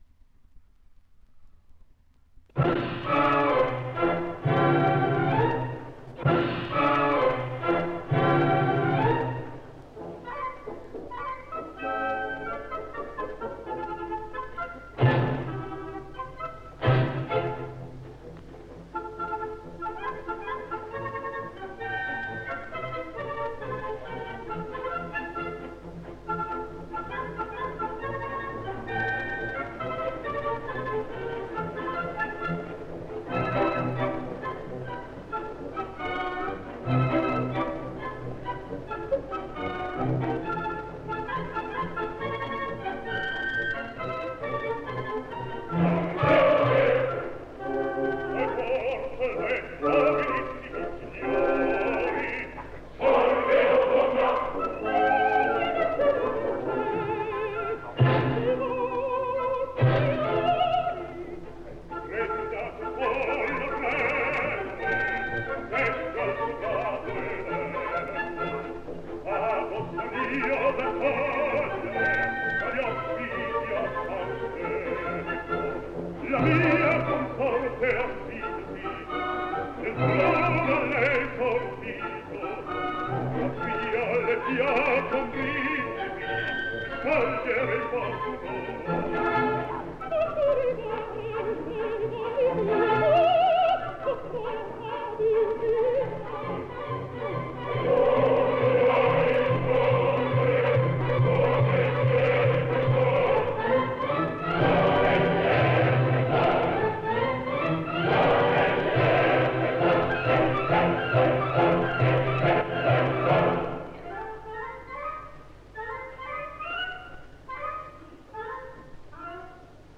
Maria Callas, dir. Victor De Sabata (1952), Leyla Gencer, dir. Vittorio Gui (1960)